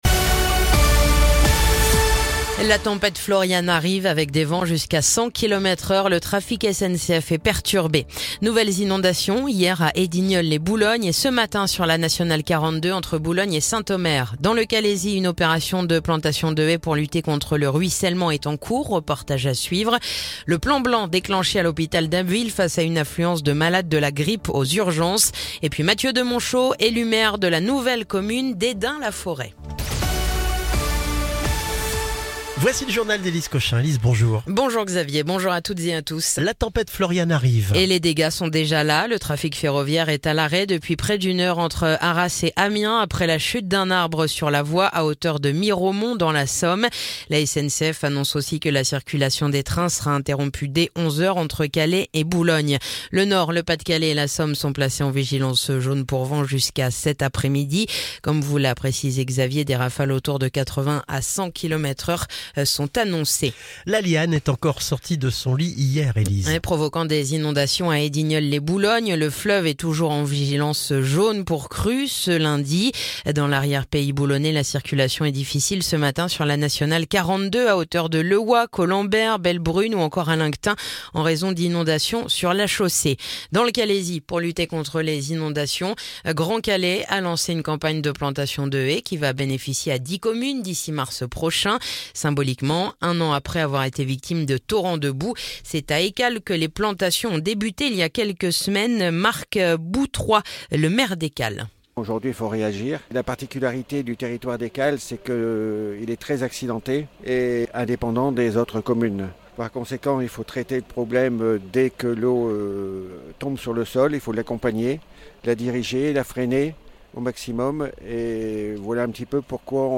Le journal du lundi 6 janvier